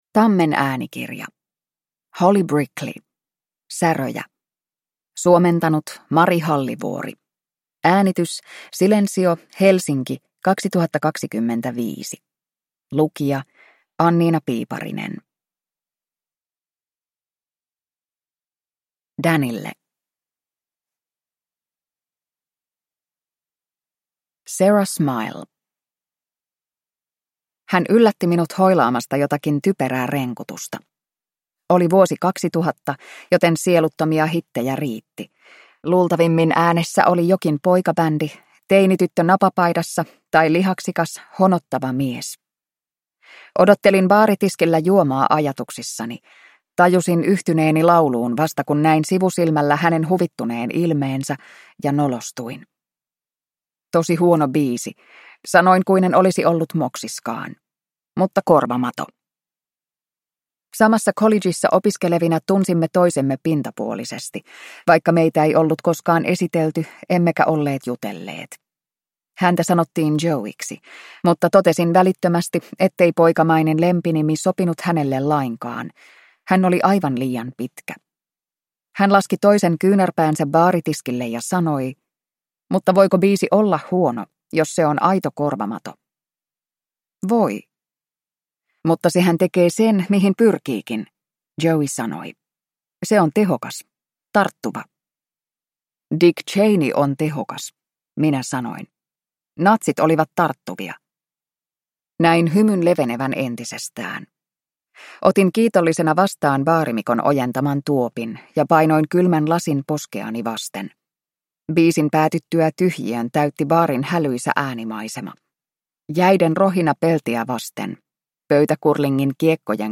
Säröjä – Ljudbok